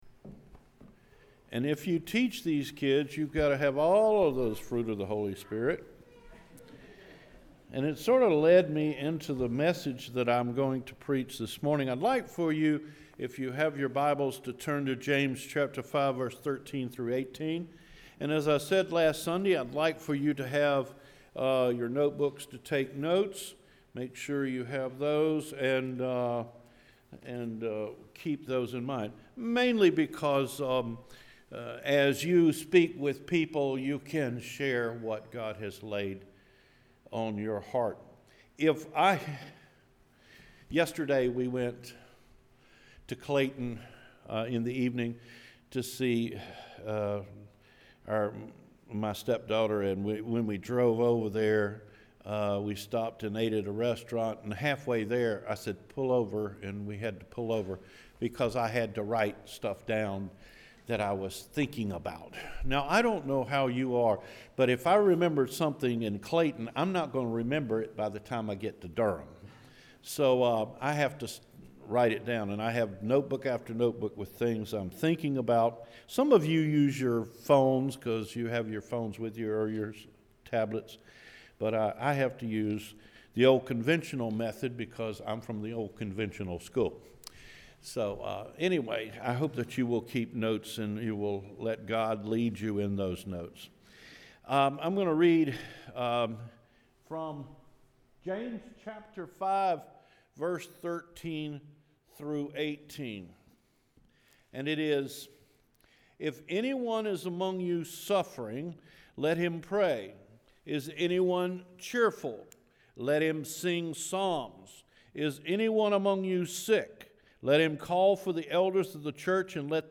Fruits of the Spirit and Prayer Life – August 6, 2017 Sermon
CedarForkSermon-8-6-17.mp3